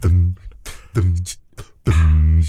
ACCAPELLA BS.wav